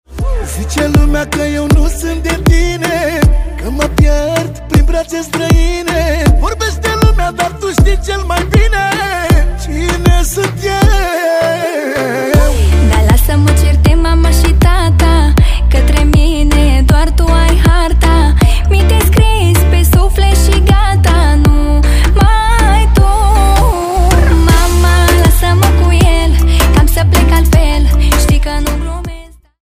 Categorie: Manele